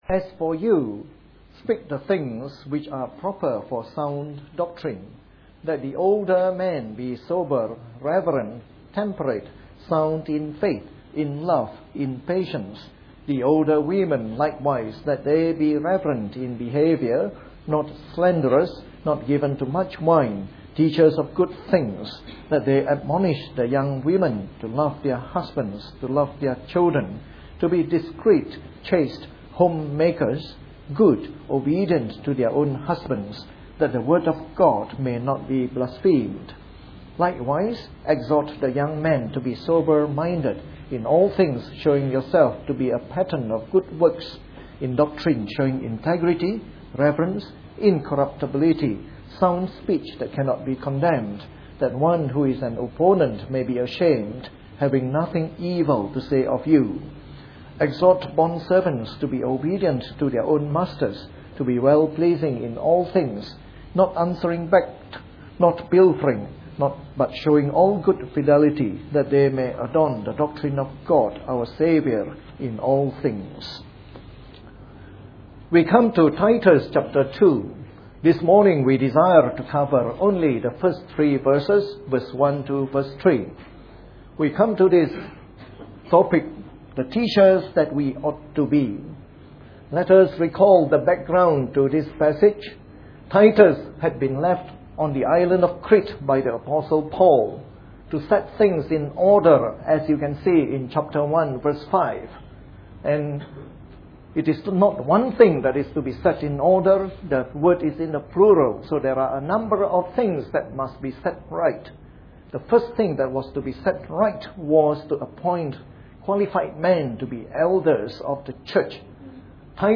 A sermon in the morning service from our series on Titus.